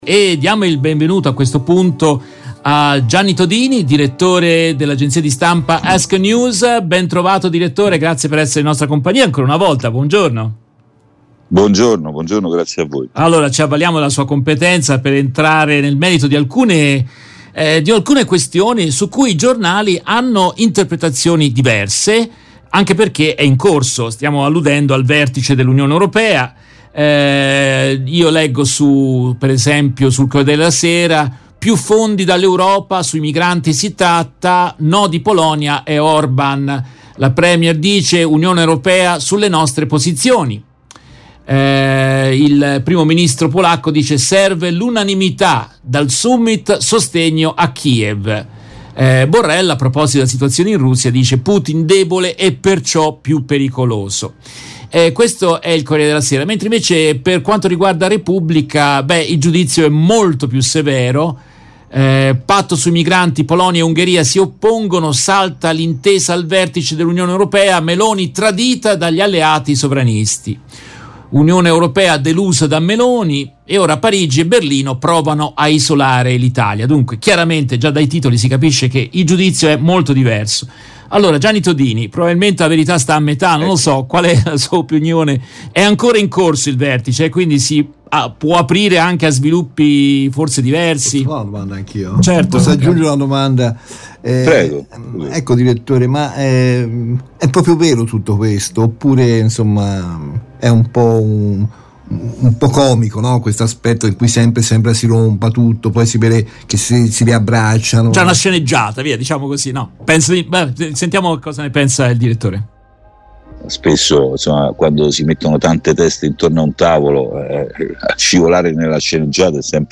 Nel corso della diretta RVS del 30 giugno 2023